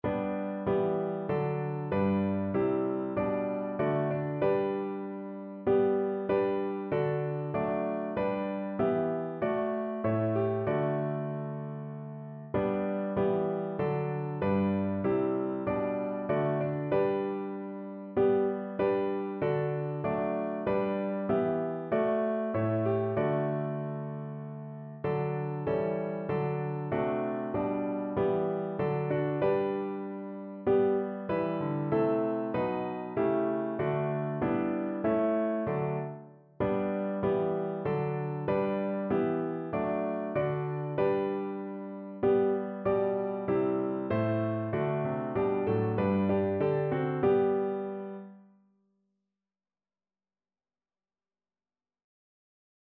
Notensatz 1 (4 Stimmen gemischt)
• gemischter Chor [MP3] 827 KB Download